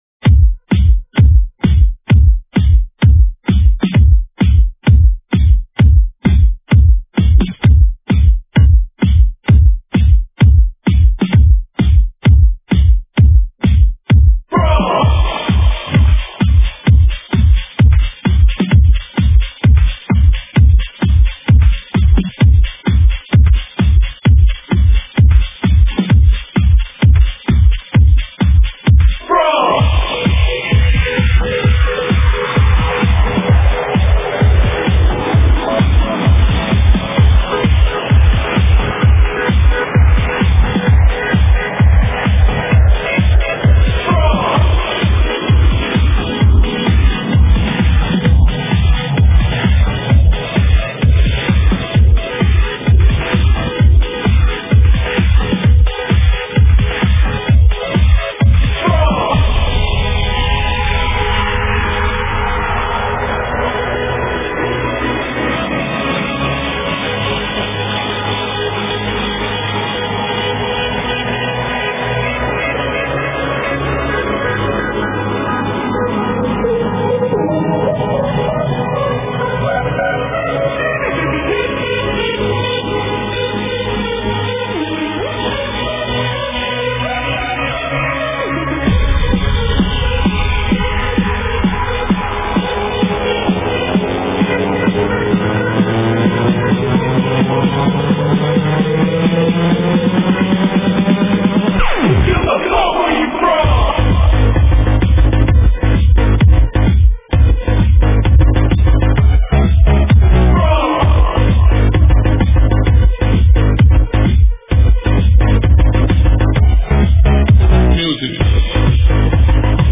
Club Music